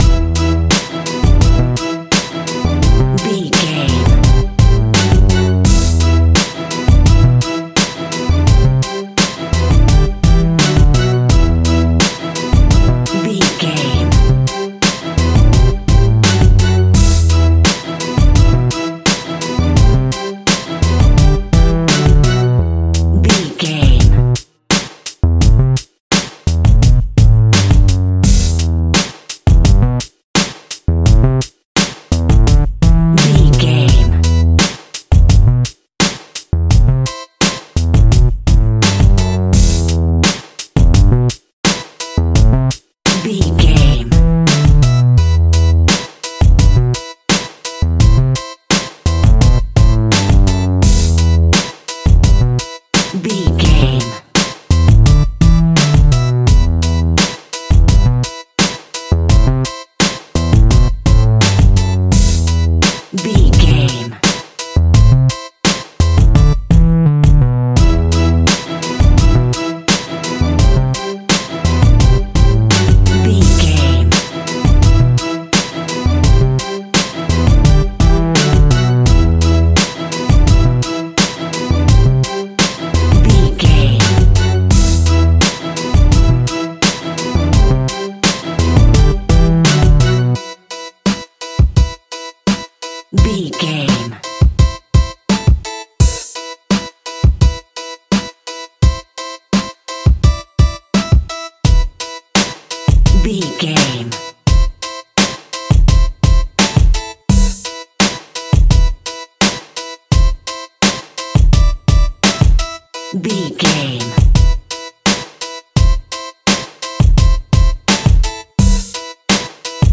Fast paced
Aeolian/Minor
SEAMLESS LOOPING?
DOES THIS CLIP CONTAINS LYRICS OR HUMAN VOICE?
aggressive
dark
funky
groovy
driving
energetic
strings
synthesiser
drum machine
hip hop instrumentals
east coast hip hop
electronic drums
synth lead
synth bass